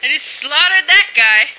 flak_m/sounds/female1/int/F1slaughter.ogg at trunk